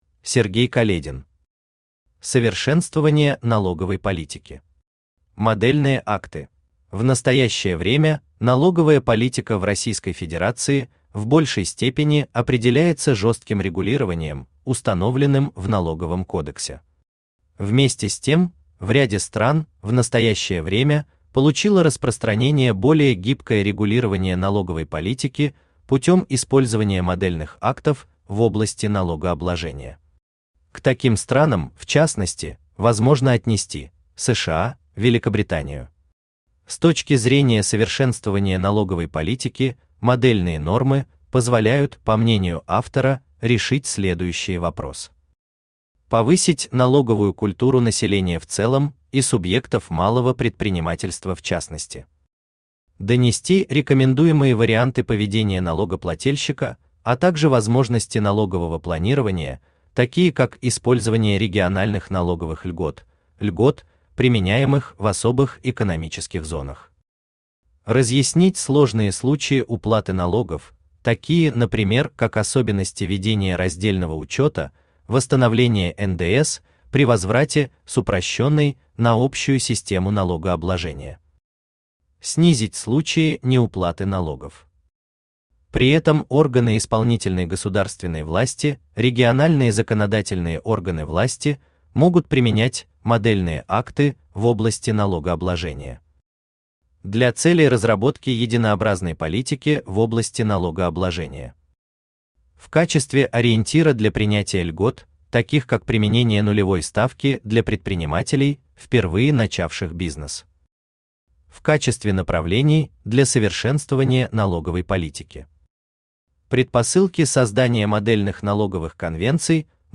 Аудиокнига Совершенствование налоговой политики.
«Модельные акты» Автор Сергей Каледин Читает аудиокнигу Авточтец ЛитРес.